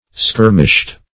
Skirmished - definition of Skirmished - synonyms, pronunciation, spelling from Free Dictionary
Skirmish \Skir"mish\, v. i. [imp. & p. p. Skirmished; p. pr. &